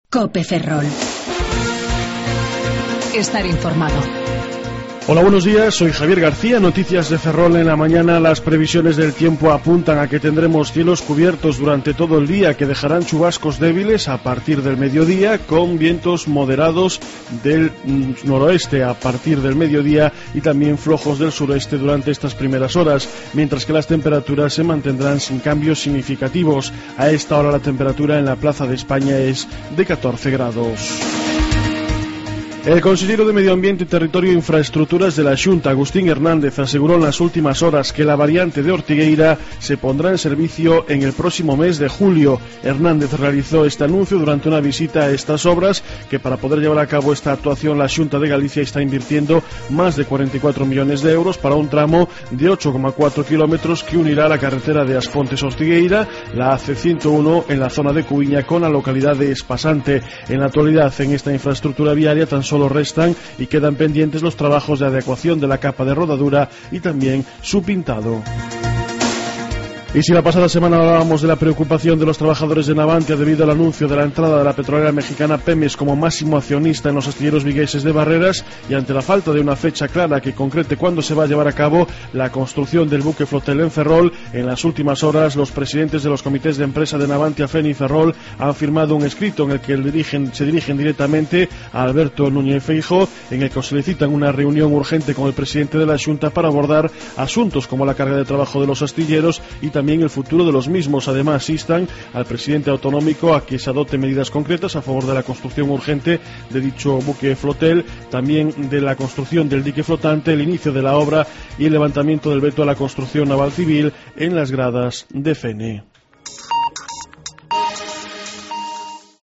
07:28 Informativo La Mañana